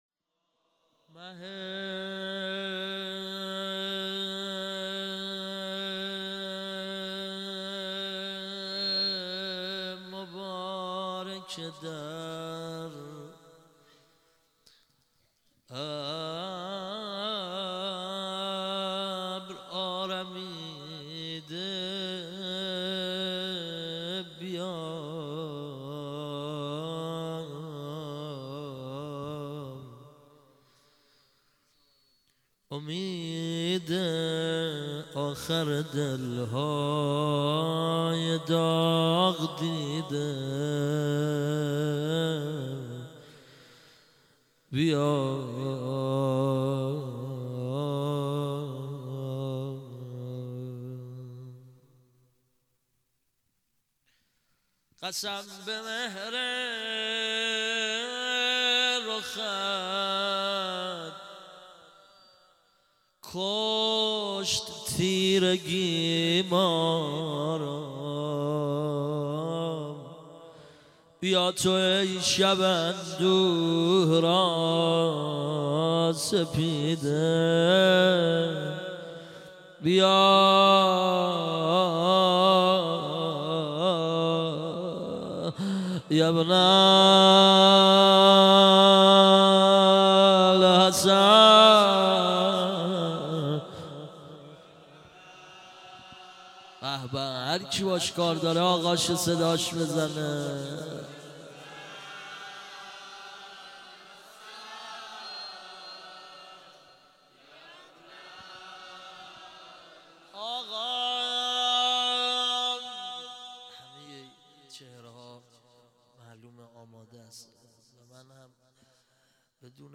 m96-sh1-monajat.mp3